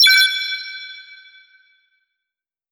升级购买.wav